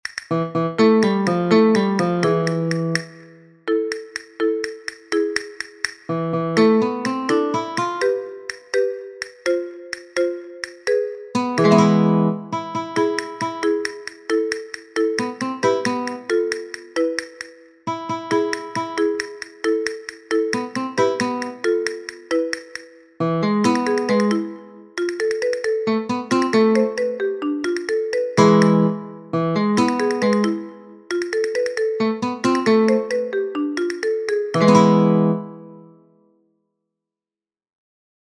It employs ascending and descending scales and the lyrics are usually funny and wittty.
Here you have got the sound file with the silent bars.
El_Vito_silencios.mp3